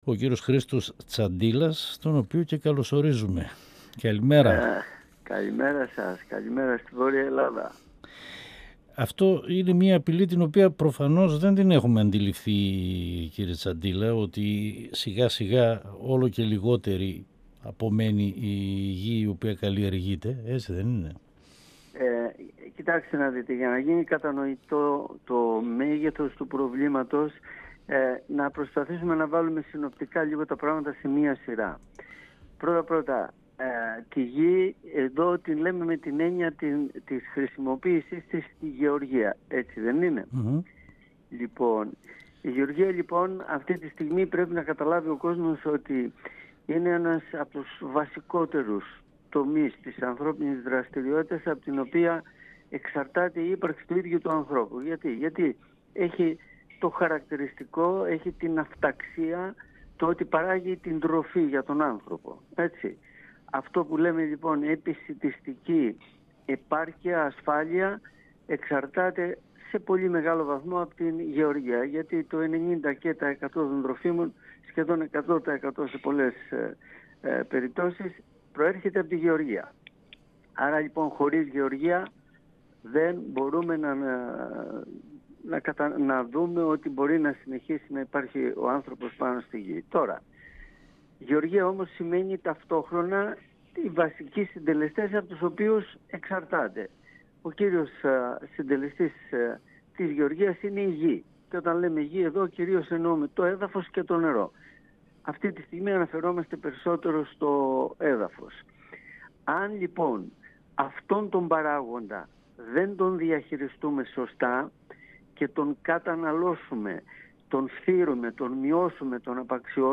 μιλώντας στην εκπομπή « Εδώ και τώρα » του 102FM της ΕΡΤ3